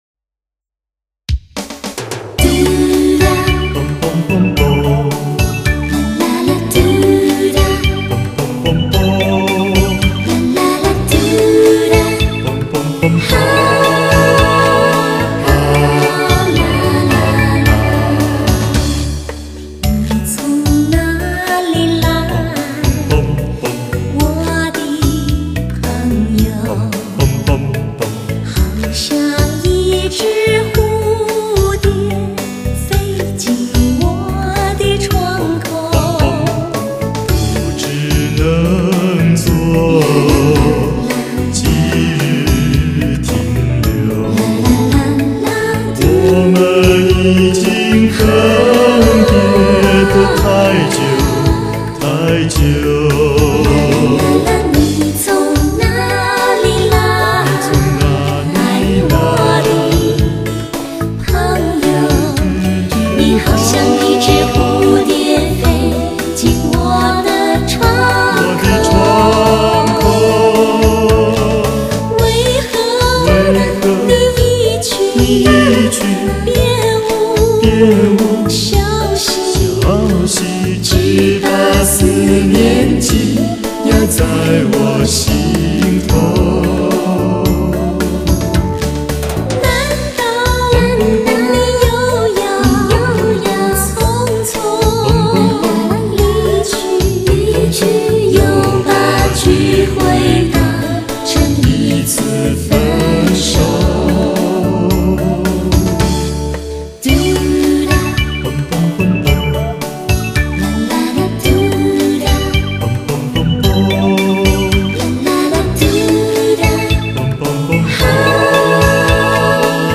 音乐类型：国语流行